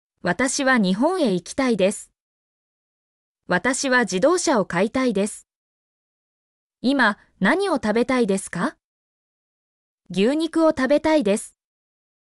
mp3-output-ttsfreedotcom-5_nSsg0SW4.mp3